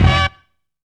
72 HIT.wav